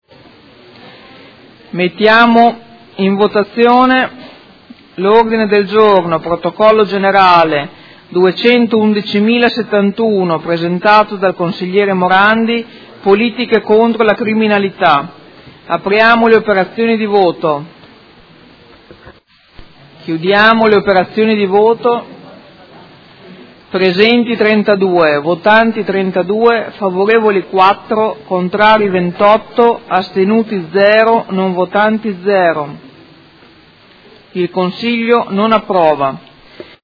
Presidente — Sito Audio Consiglio Comunale
Seduta del 20/12/2018. Mette ai voti Ordine del Giorno Prot. Gen. 211071